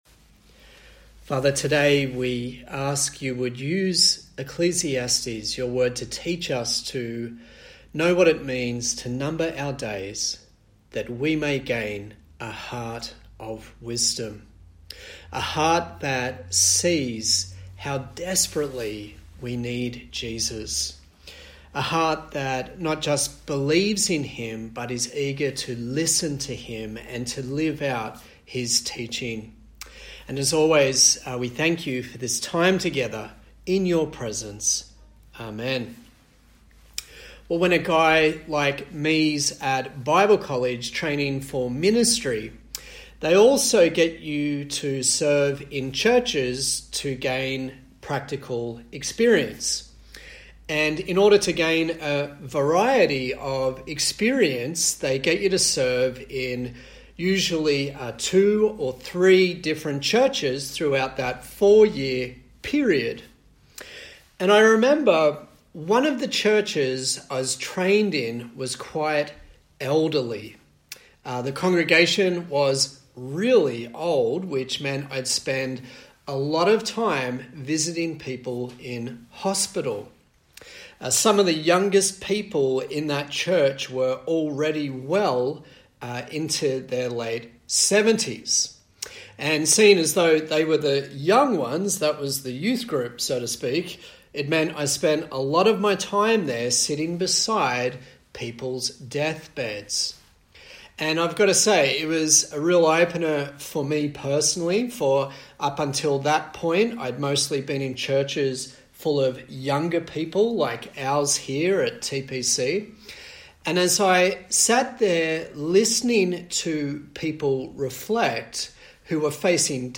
A sermon in the series on the book of Ecclesiastes
Service Type: Sunday Morning